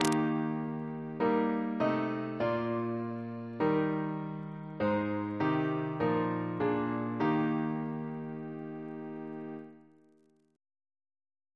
CCP: Chant sampler
Single chant in E♭ Composer: John Travers (1703-1758) Reference psalters: OCB: 296